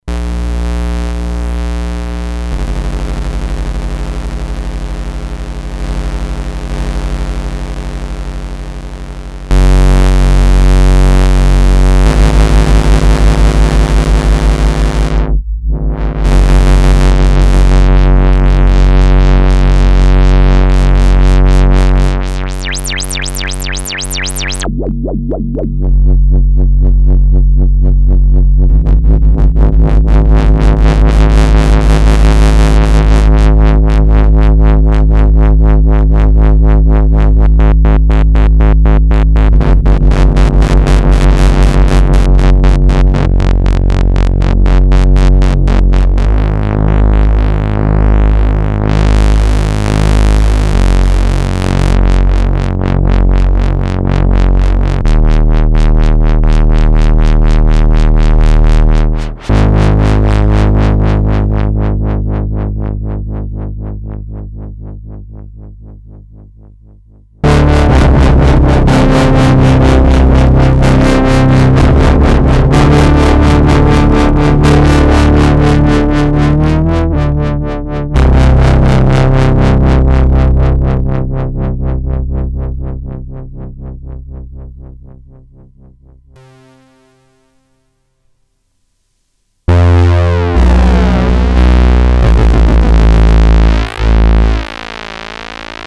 Programmable Preset Polyphonic Synthesizer
Noise and LFO test